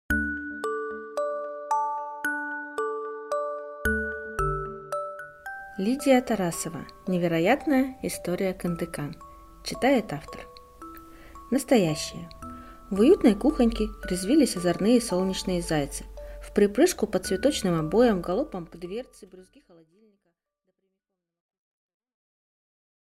Аудиокнига Невероятная история Кындыкан | Библиотека аудиокниг